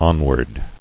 [on·ward]